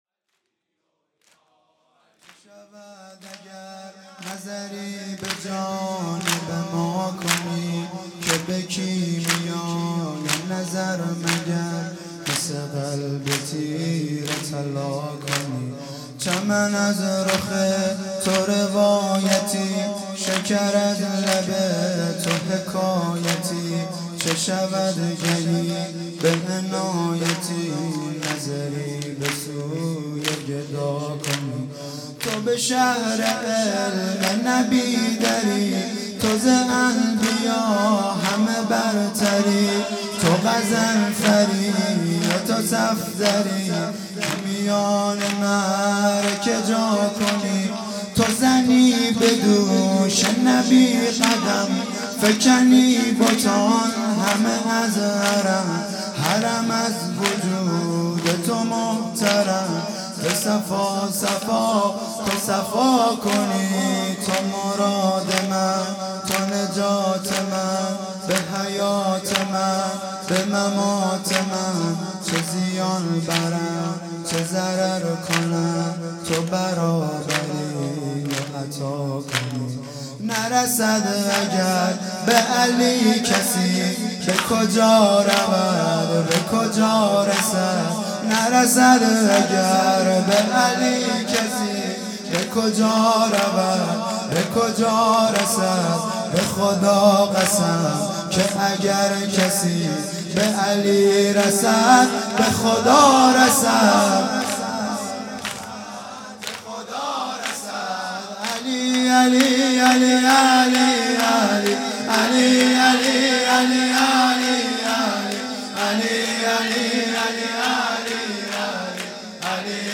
چه شود اگر|شب هفتم محرم ۹۵